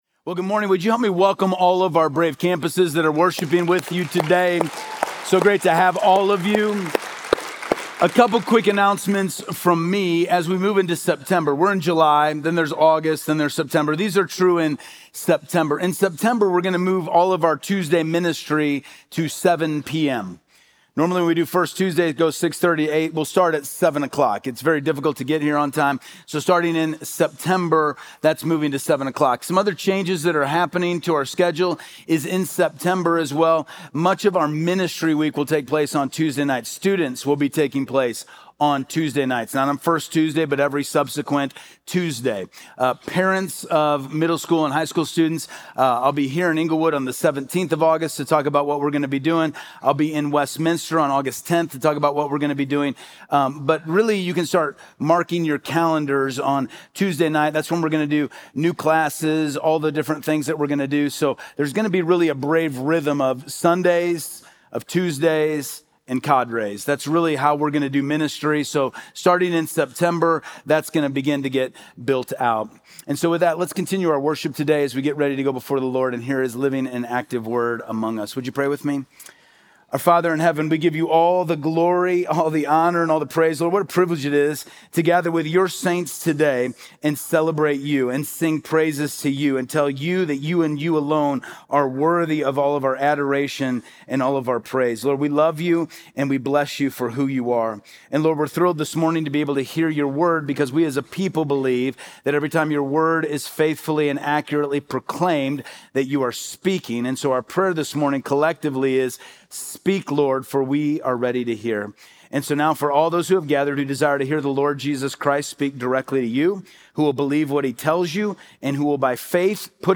Church